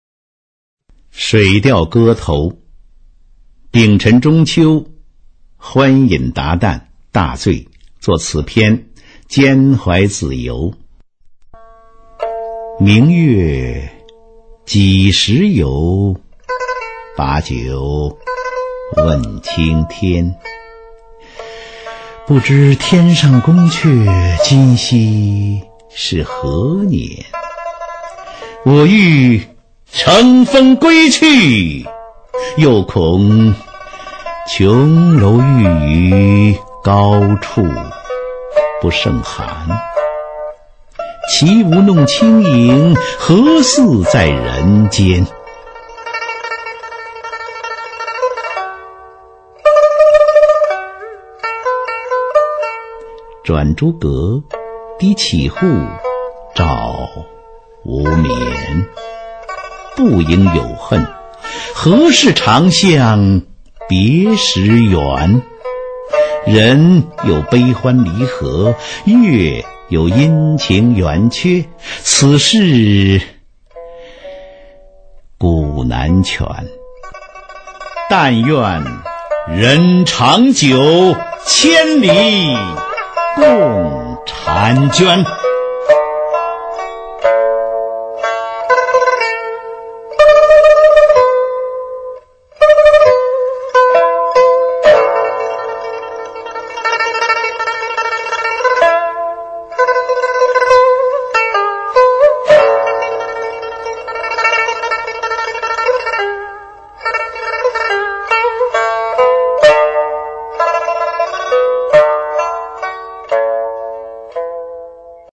[宋代诗词诵读]苏轼-水调歌头3（男） 宋词朗诵